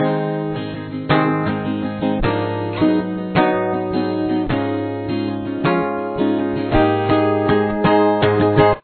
You must use a capo on the fourth fret for this song.